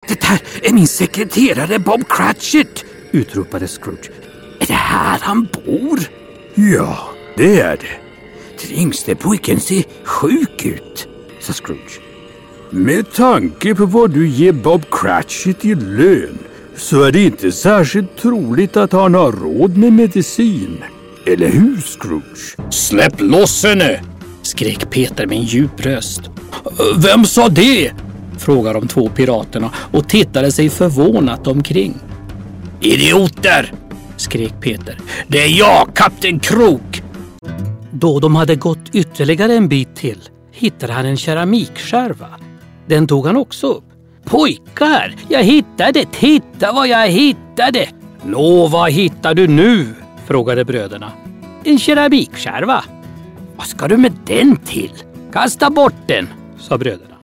Upscale, corporate, elegant, ironic
Sprechprobe: Sonstiges (Muttersprache):